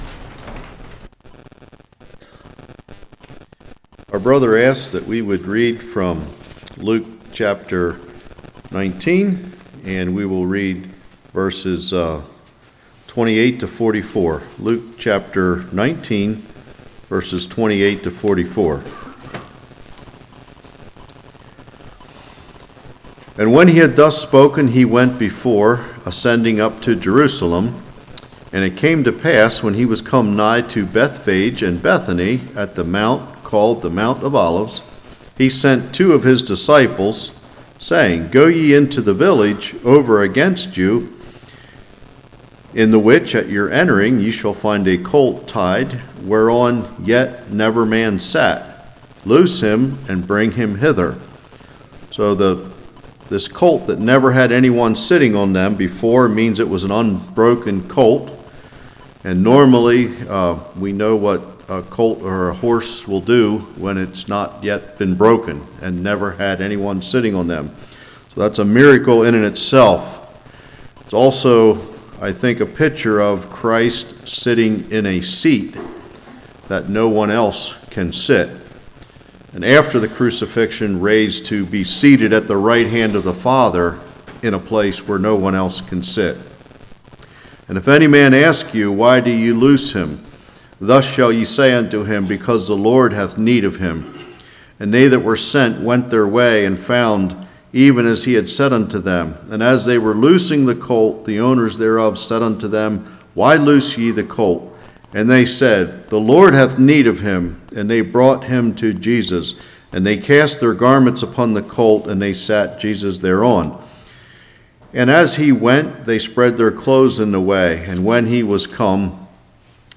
Luke 19:29-44 Service Type: Morning Groups of People